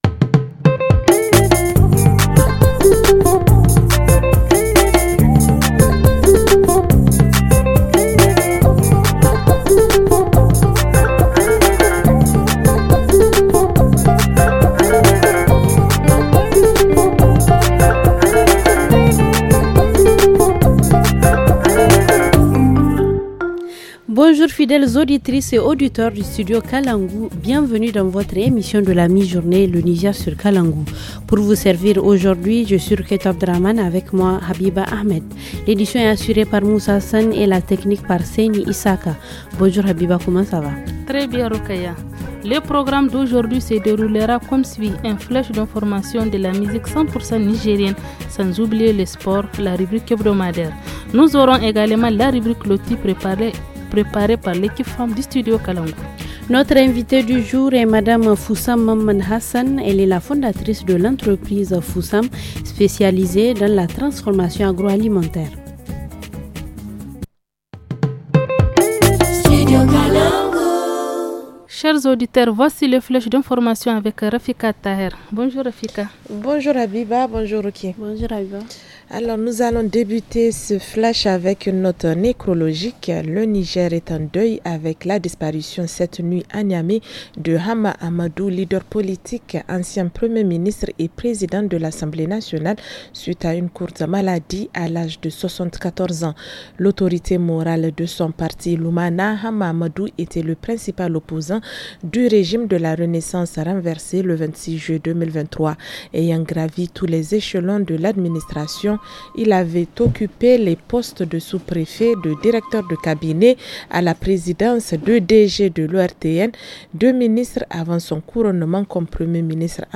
Le titre « Sarkin Abzin » chanté par la troupe Albichir de Tchirozerine